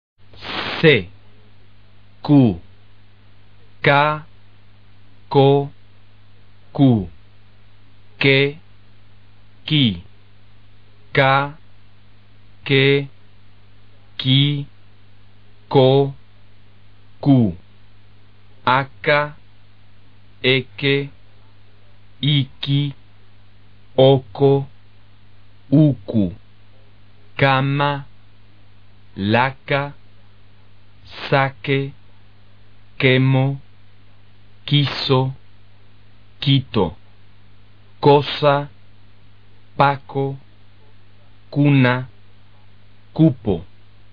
C Q 的发音：
因此，这两个字母的读音是一样的。